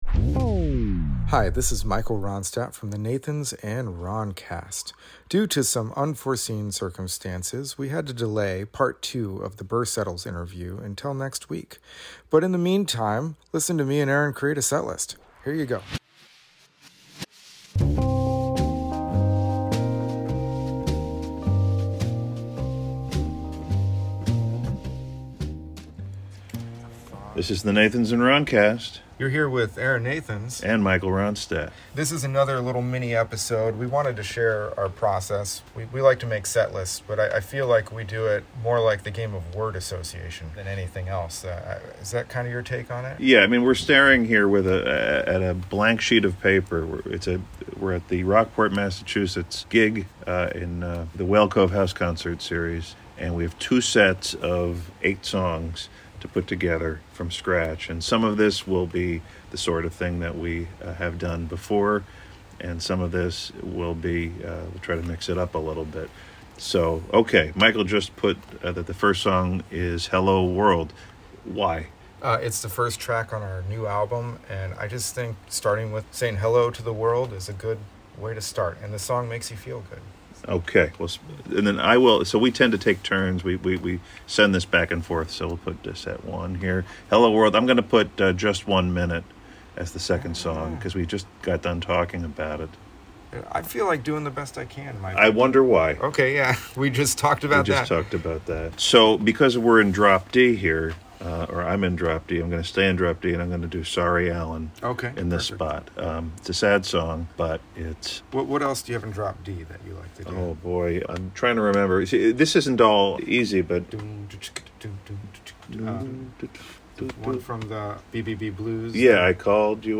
In this case, we were sitting in front of an open window in the early fall in Rockport, Mass., waiting to the play the Whale Cove series, which was made up of two 45-minute sets and an intermission.